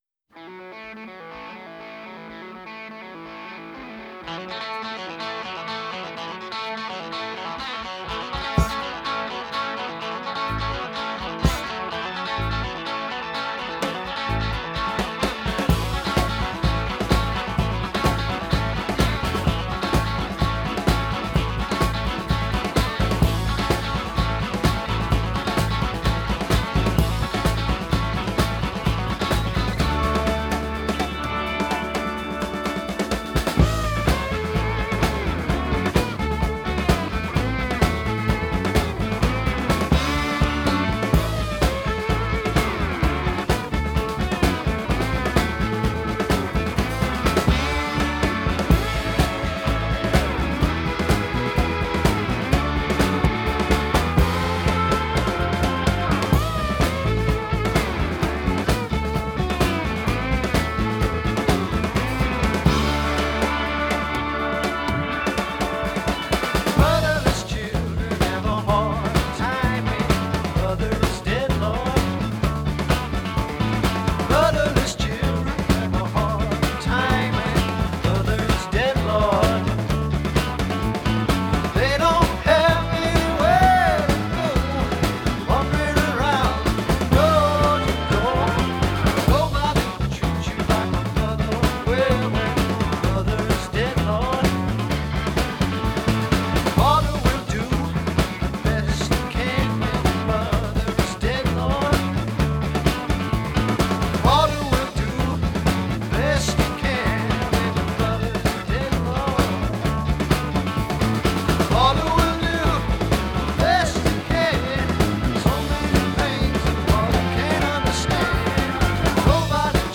Blues Rock, Classic Rock